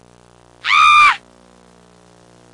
Scream Sound Effect
Download a high-quality scream sound effect.
scream-1.mp3